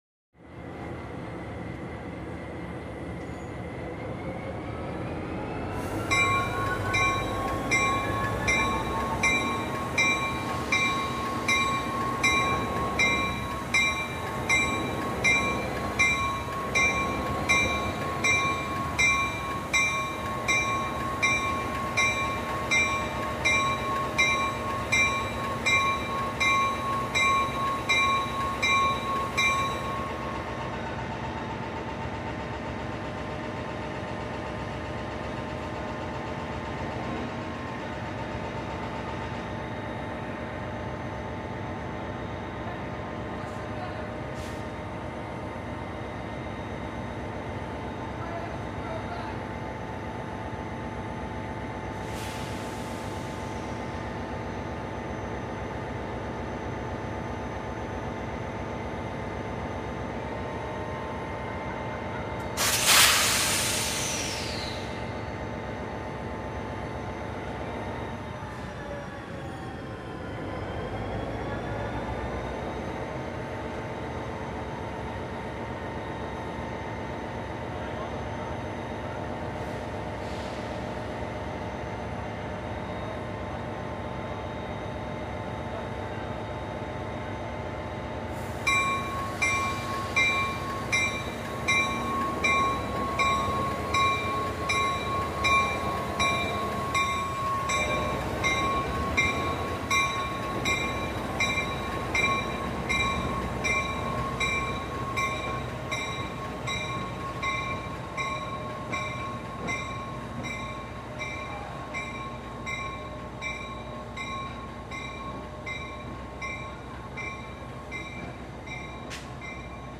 Train Station - Quiet Station